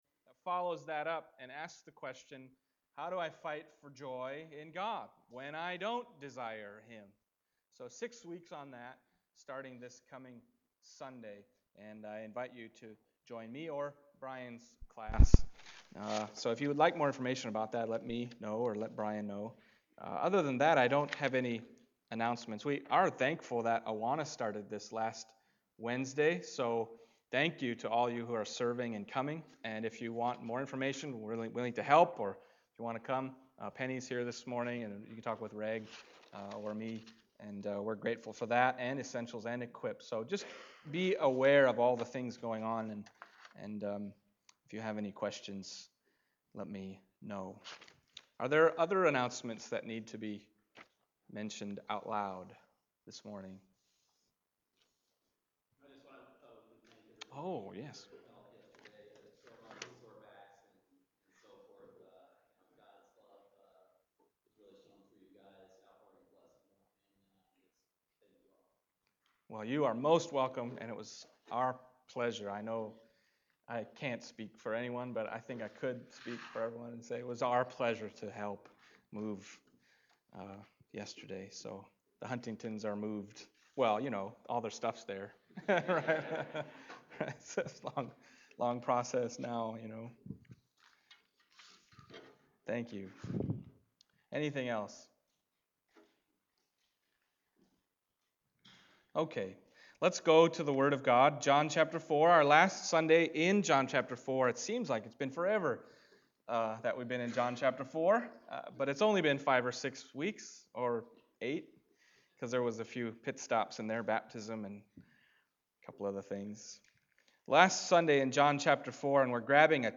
John Passage: John 4:43-54 Service Type: Sunday Morning John 4:43-54 « The Food of Jesus Christ Death Wish